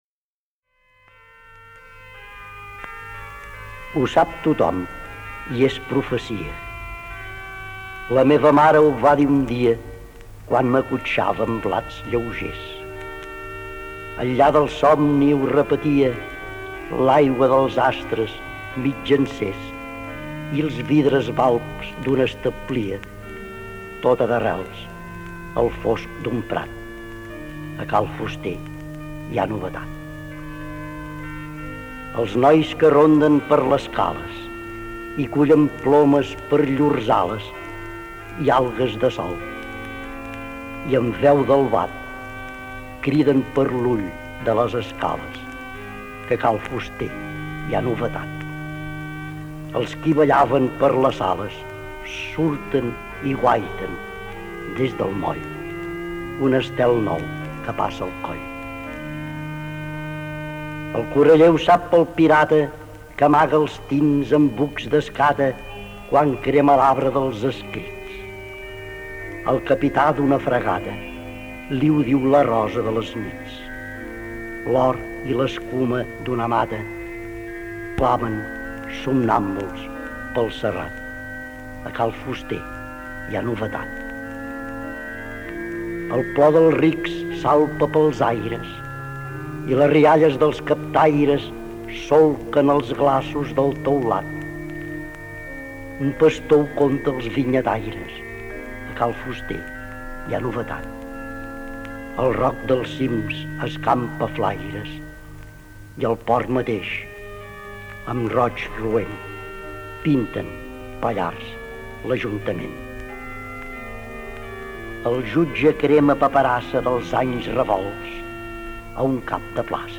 Aquest any us deixaré un clàssic de Nadal i de les lletres catalanes, el poeta J.V.Foix recitant “Ho sap tothom, i és profecia